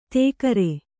♪ tēkare